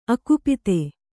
♪ akupite